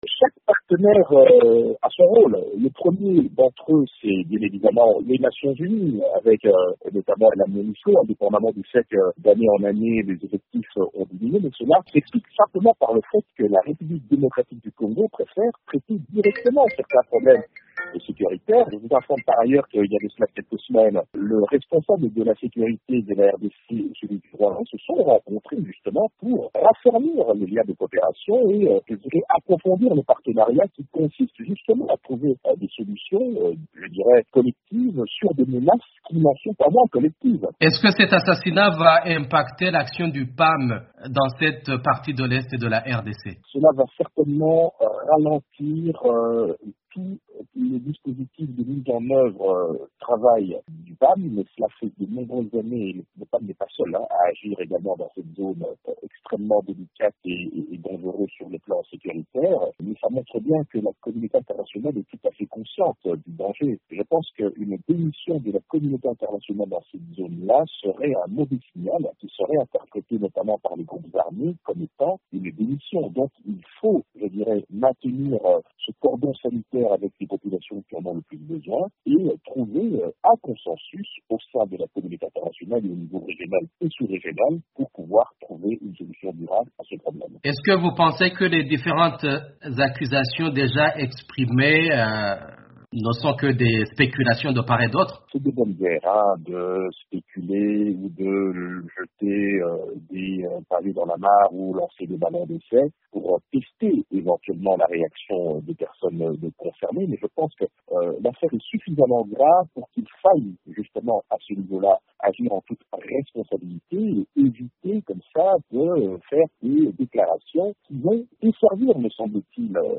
a joint à Kinshasa, l’analyste politique